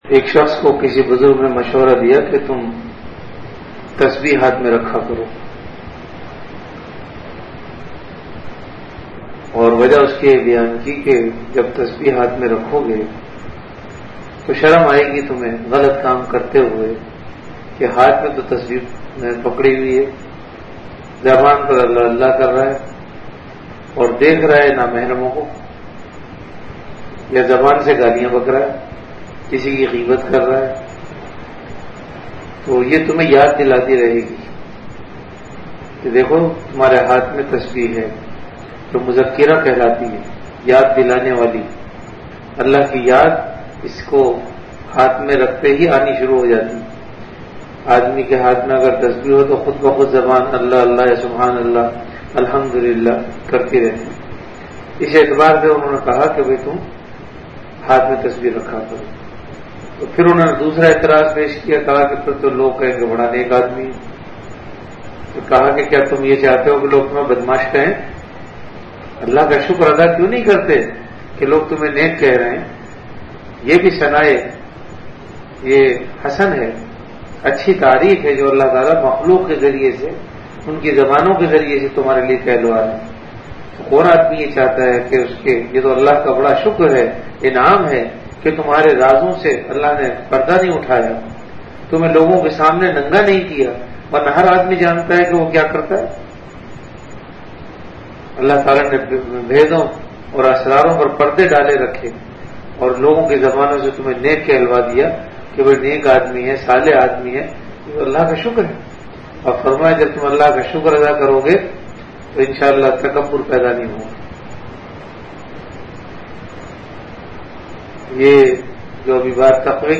Delivered at Home.
Event / Time After Isha Prayer